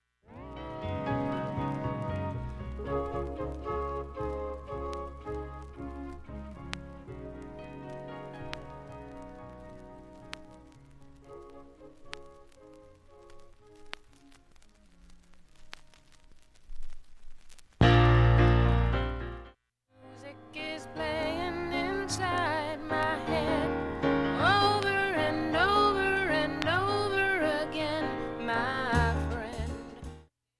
盤面きれいです音質良好全曲試聴済み。
A-2終わりフェイドアウト部に
かすかなプツが７回出ます。
B-1始めにかすかなプツが２回出ます。
ほか単発のかすかなプツが２箇所